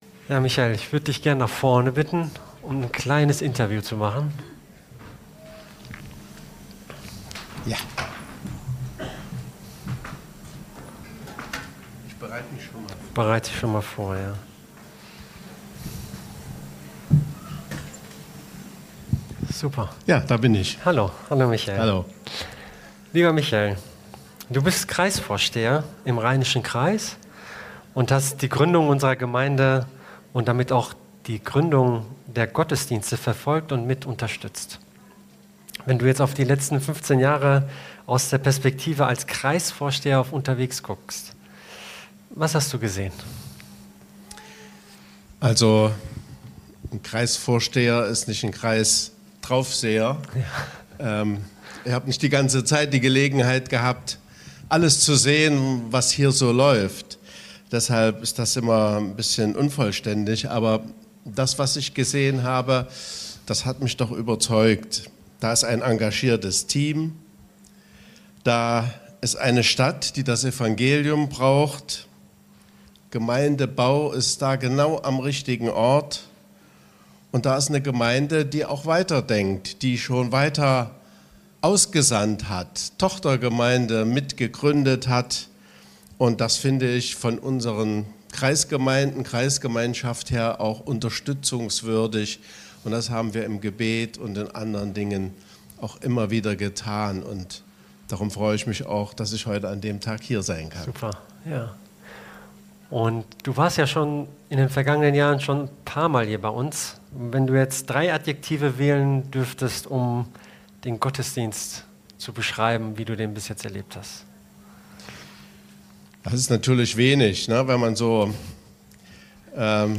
War/Ist der Satz wörtlich zu nehmen oder geht es um etwas viel größeres als nur leiblich satt zu werden? Und was hat das alles mit dem christlichen Glauben an das Leben nach dem (physischen) Tod zu tun? Zur Info: Während des Gottesdienstes wurden Folien mit den entsprechenden Zahlen der genannten Umfrage zur Frage nach dem "Leben nach dem Tod" gezeigt.